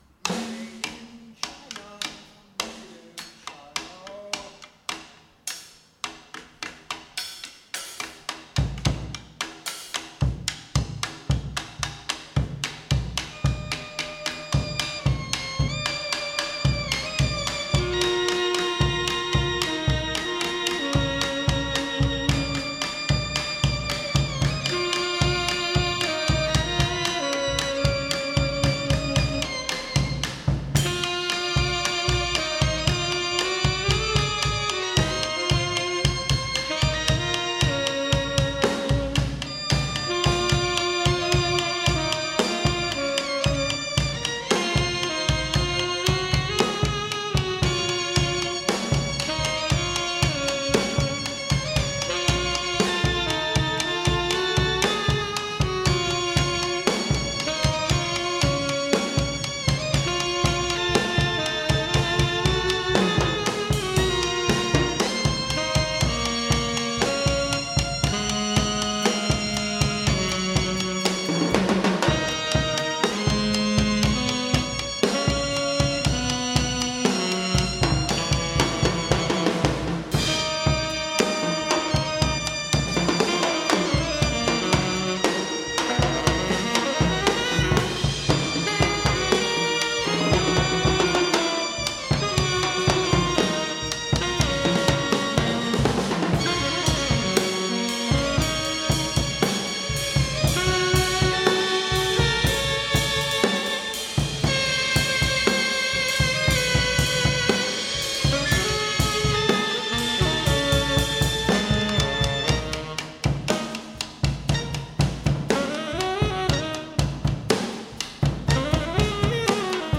Recorded live at the 39th Street loft, Brooklyn.
drums, vocal
alto saxophone with mechanism
tenor saxophone, voice
Stereo (Metric Halo / Pro Tools)